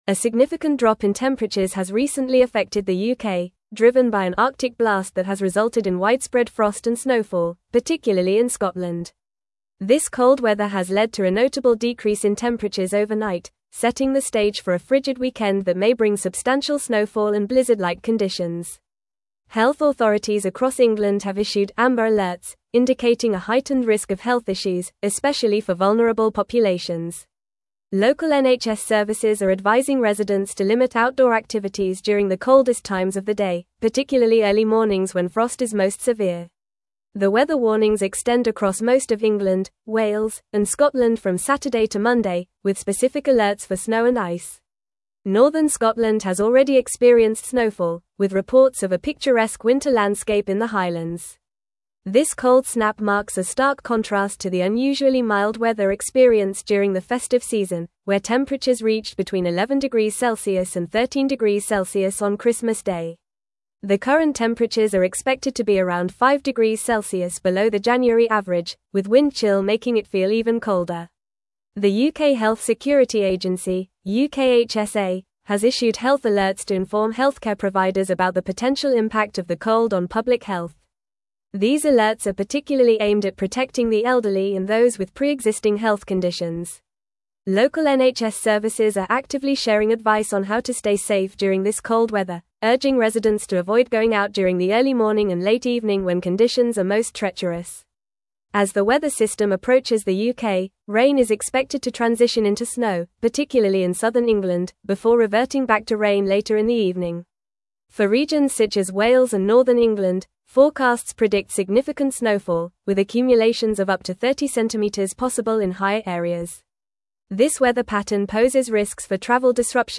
Fast
English-Newsroom-Advanced-FAST-Reading-UK-Faces-Bitter-Cold-and-Heavy-Snow-This-Weekend.mp3